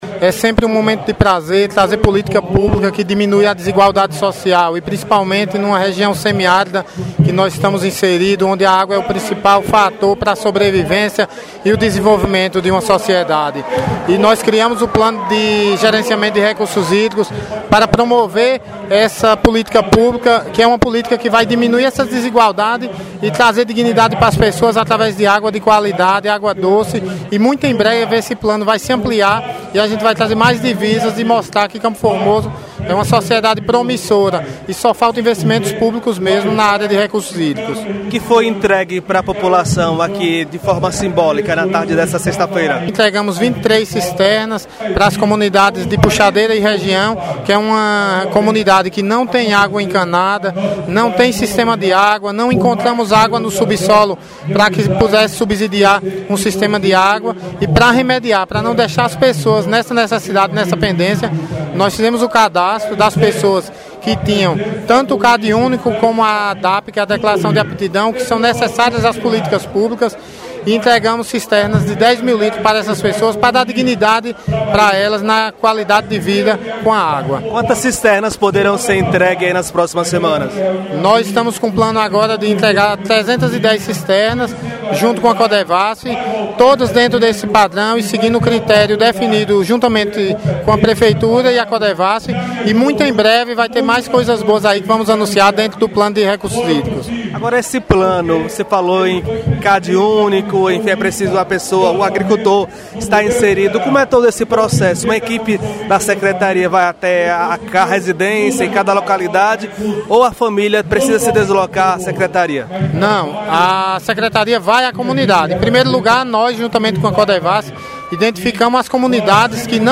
Secretário Rangel Carvalho fala sobre a importância de políticas públicas